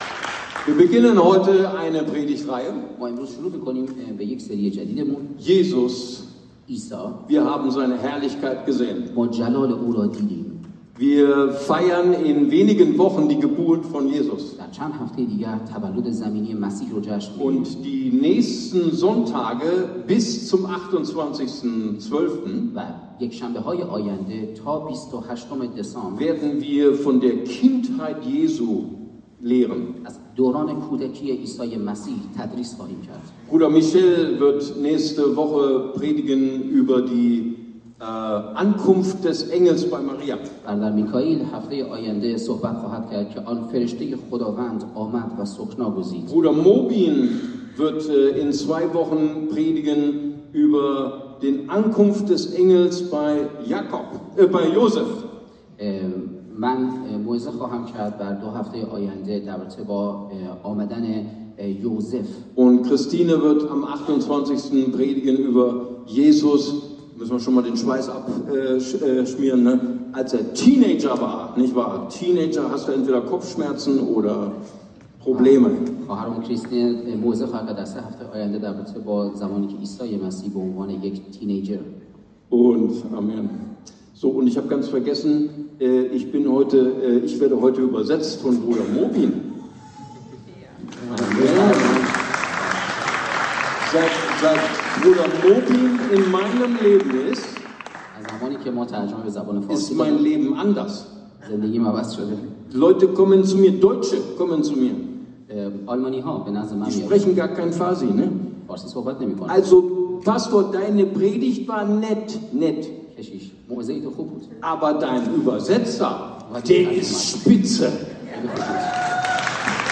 ICB Predigtreihe Advent & Weihnachten 2025